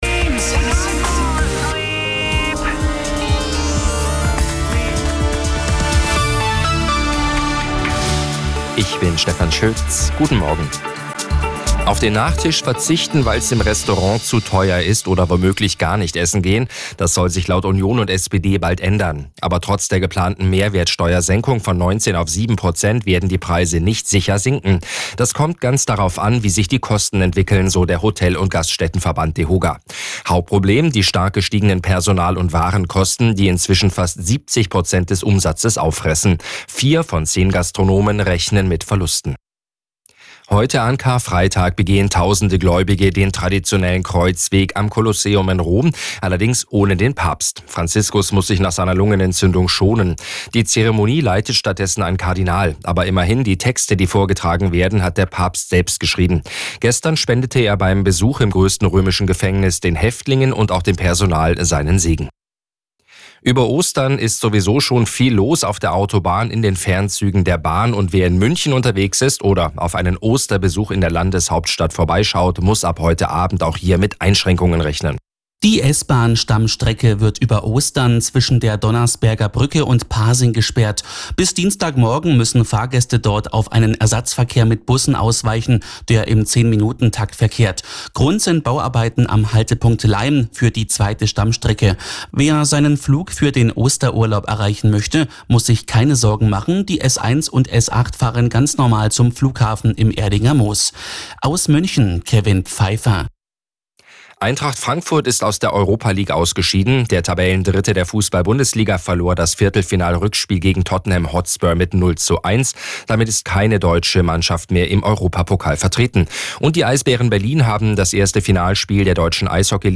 Die Radio Arabella Nachrichten von 10 Uhr - 07.06.2025